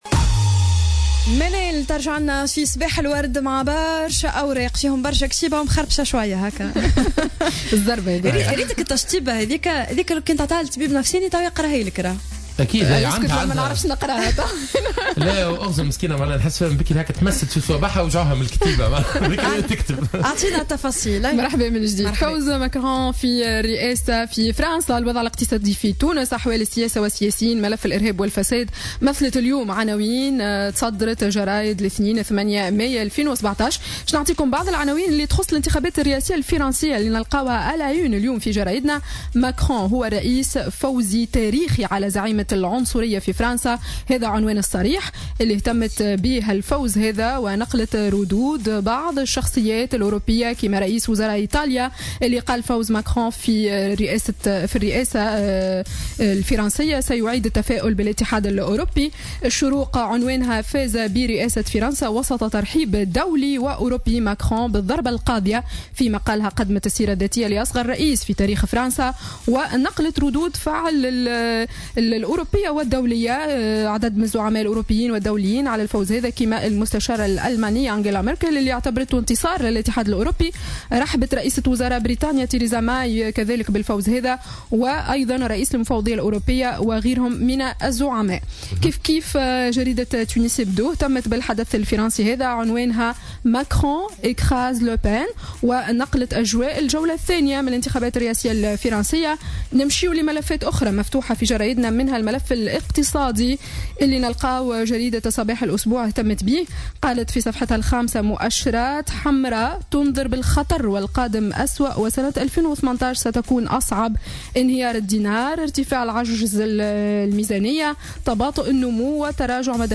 Revue de presse du lundi 08 mai 2017